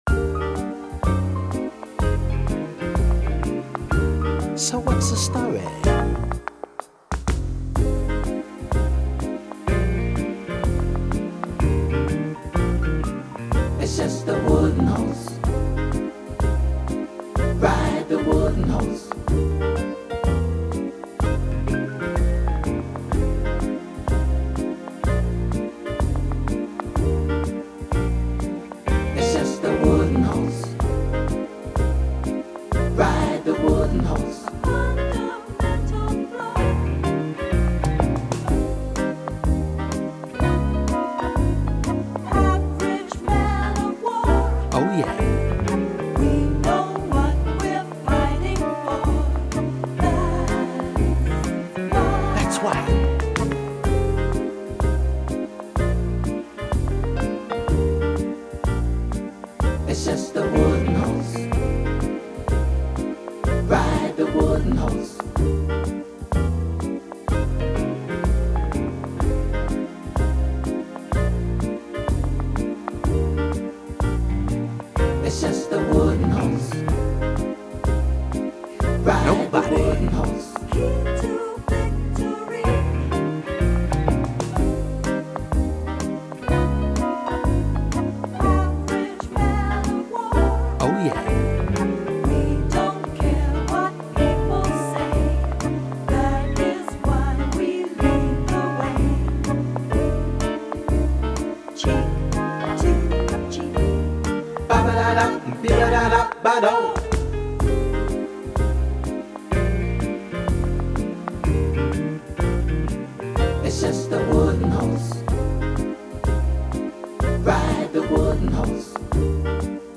Song style: tap
Download the part vocal (harmony) version of the song